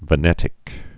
(və-nĕtĭk)